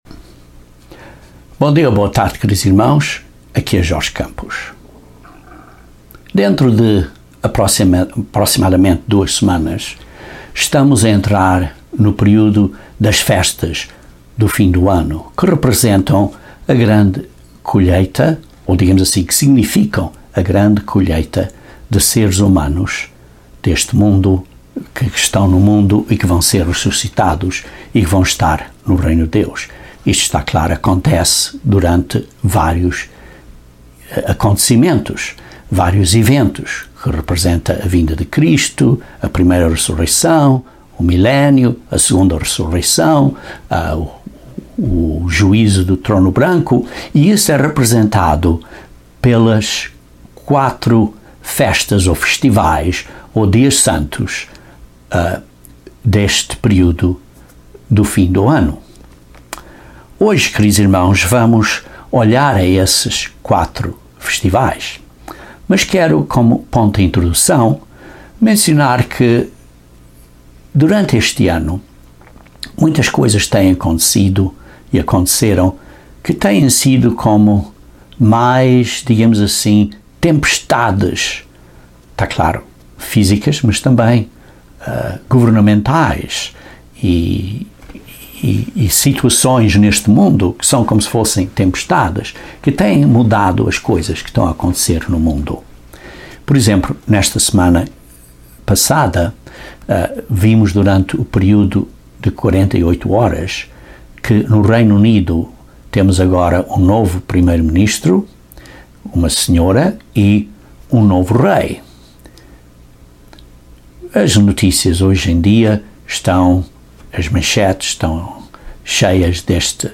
Os quatro últimos dias de Festa de Deus têm um significado importante para o tempo do fim. Apontam para a colheita de Deus de seres espirituais com vida eterna, a última fase do plano de salvação de Deus. Este sermão dá uma visão geral destes dias.